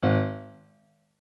MIDI-Synthesizer/Project/Piano/12.ogg at 51c16a17ac42a0203ee77c8c68e83996ce3f6132